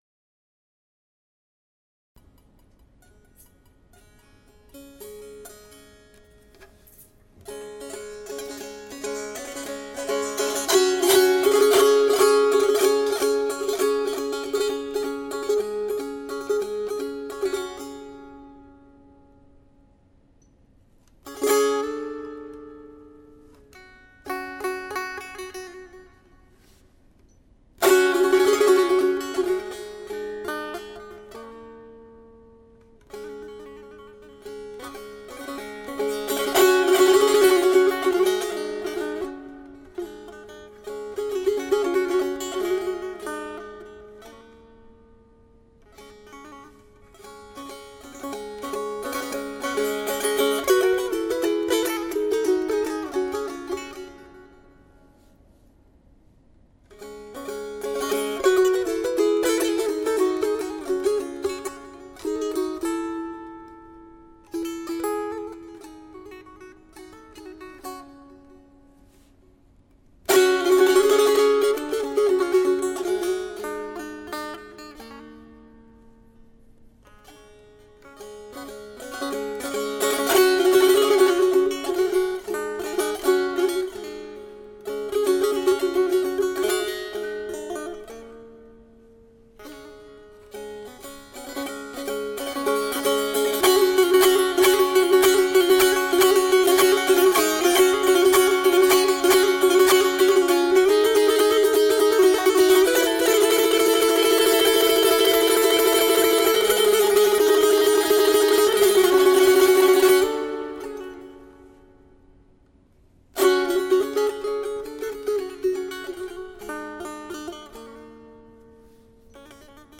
اجرای تک نوازی دوتار
بی کلام
دوتار